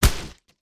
ConcreteHit06.wav